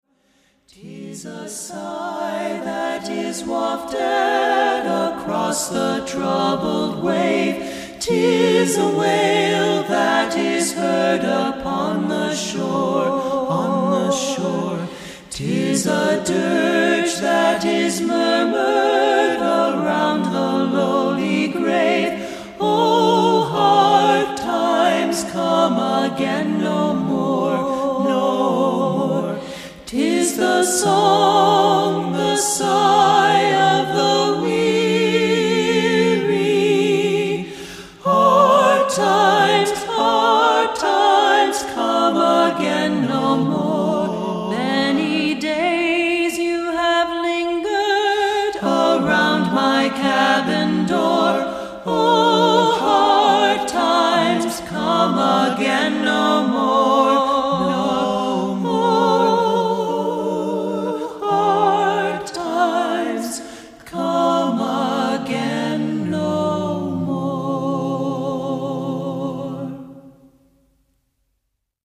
Accompaniment:      None
Music Category:      Christian